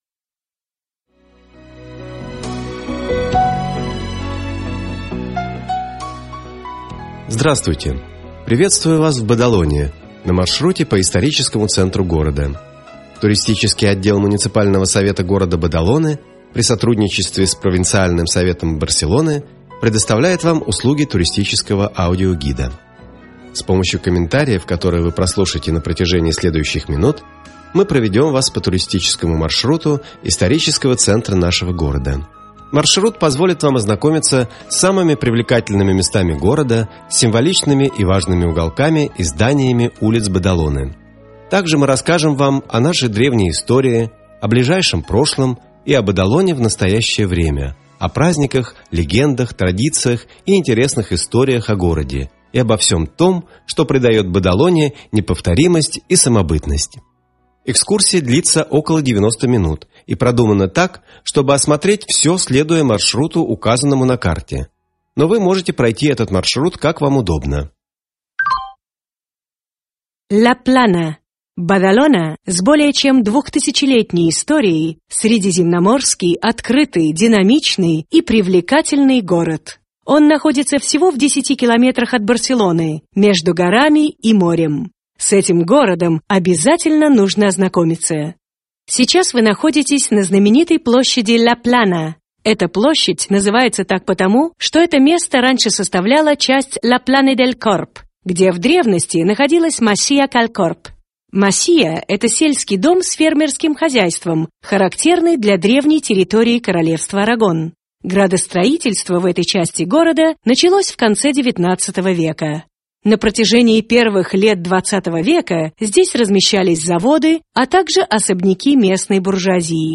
Ruta turística audioguiada pel centre històric